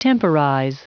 Prononciation du mot temporize en anglais (fichier audio)
Prononciation du mot : temporize
temporize.wav